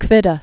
(kvĭdə), Ludwig 1858-1941.